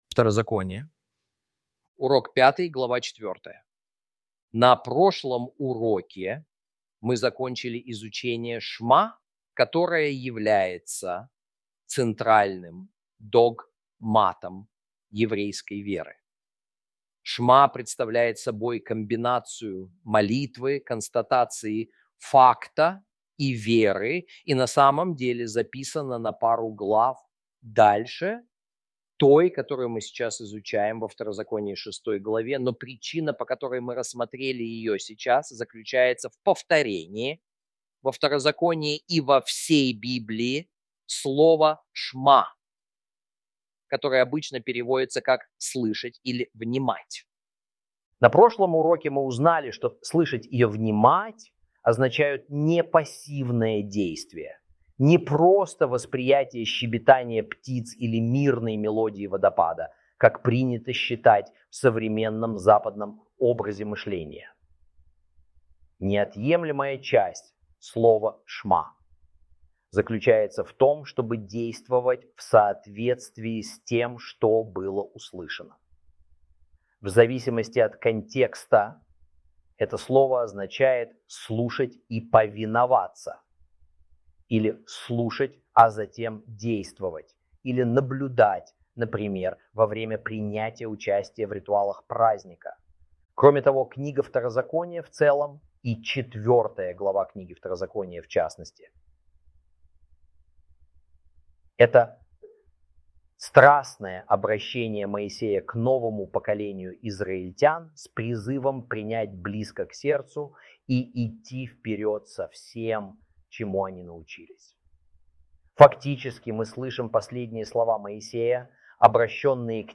ru-audio-deuteronomy-lesson-5-ch4.mp3